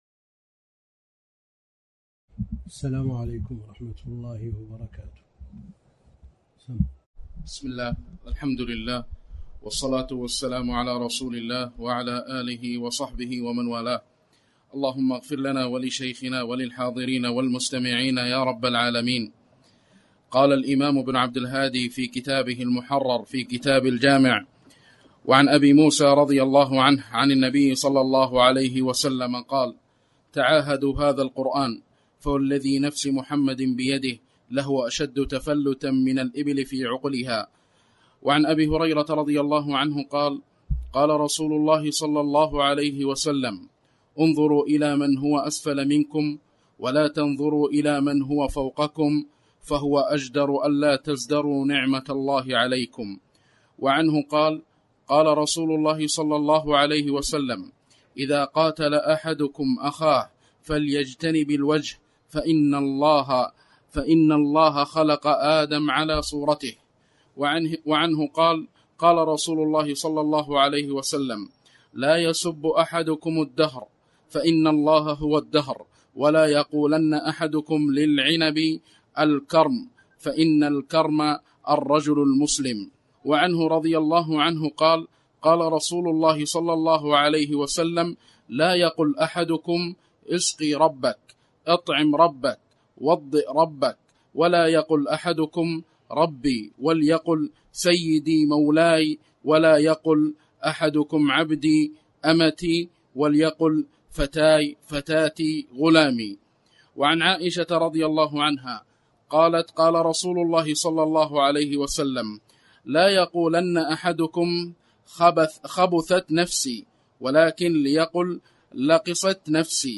تاريخ النشر ٦ ذو القعدة ١٤٣٨ هـ المكان: المسجد الحرام الشيخ: فضيلة الشيخ د. عبد الكريم بن عبد الله الخضير فضيلة الشيخ د. عبد الكريم بن عبد الله الخضير كتاب الجامع